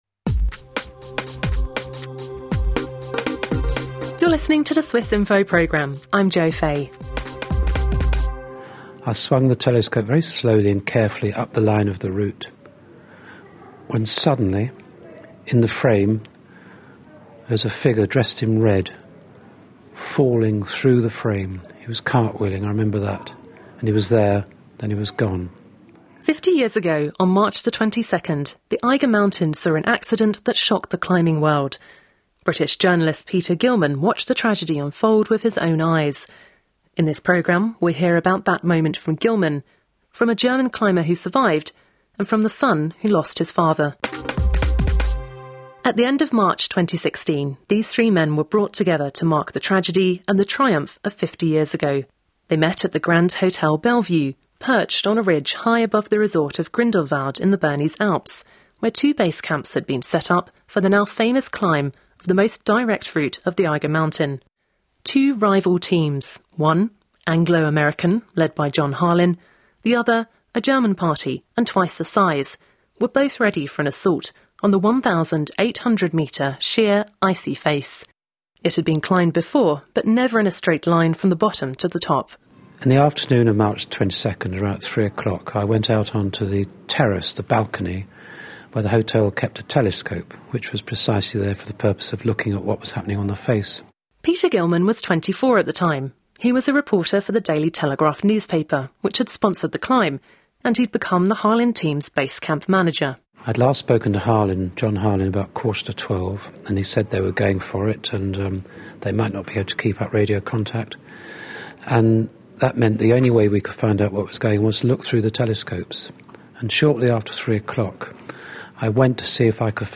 50 years ago, the Eiger mountain saw an accident that shocked the climbing world. Hear about that moment from a journalist, a climber who survived, and from the son who lost his father.